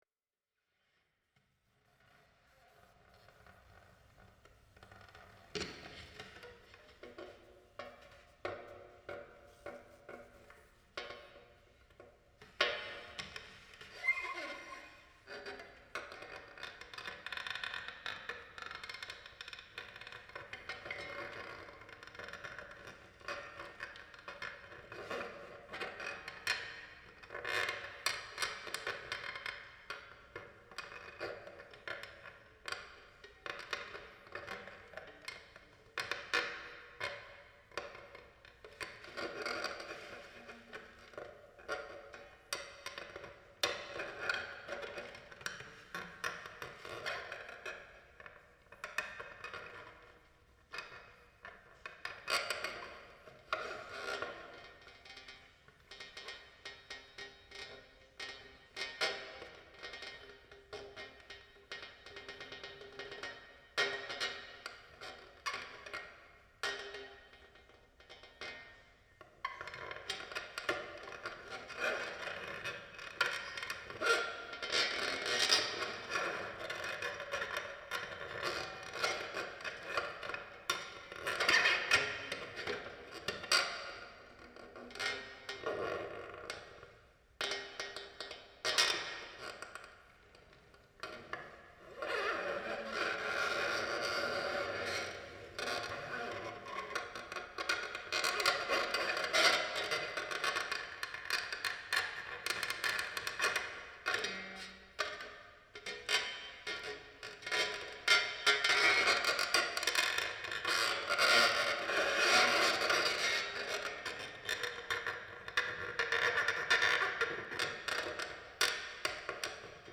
Danza Butoh.
Acompañamiento sonoro